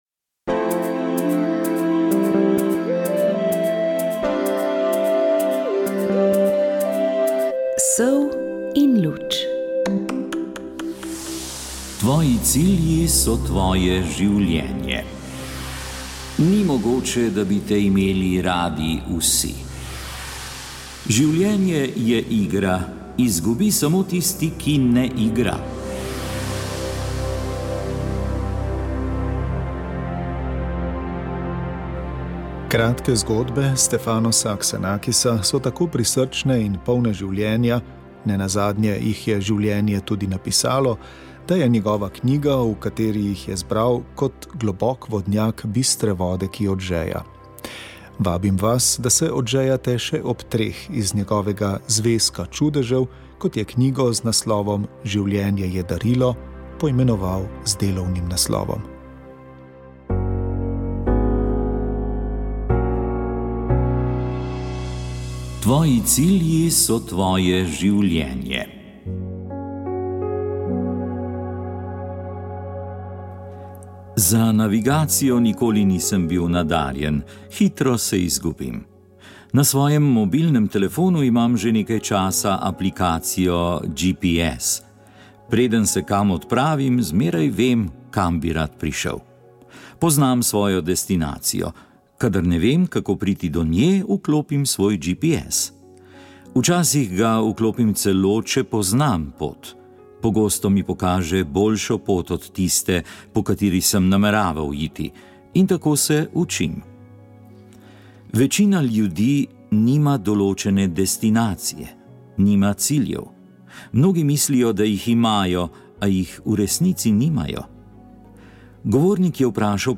Rožni venec
Molili so molilci pobude Molitev in post za domovino